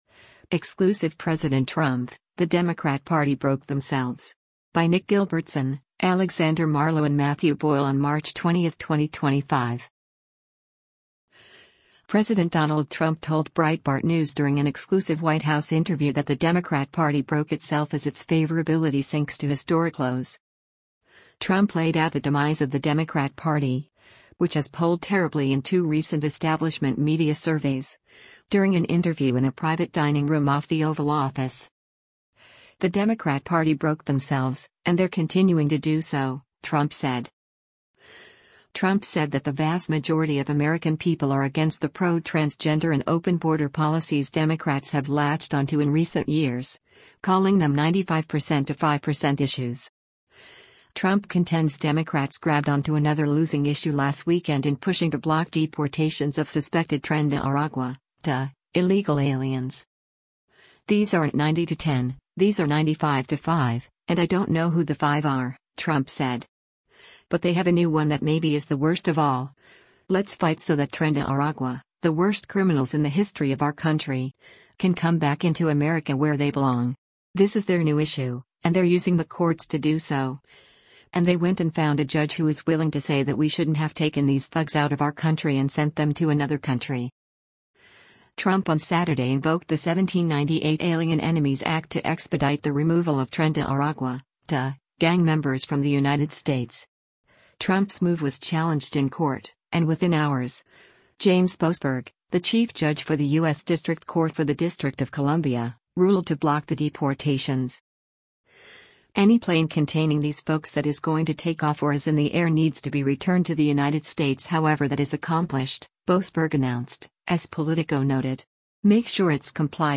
Trump laid out the demise of the Democrat Party, which has polled terribly in two recent establishment media surveys, during an interview in a private dining room off the Oval Office.